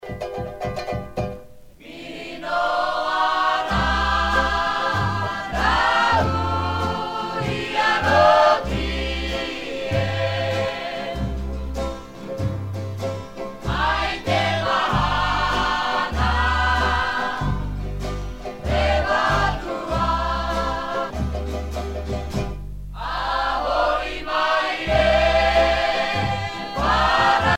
Groupe folklorique
Pièce musicale éditée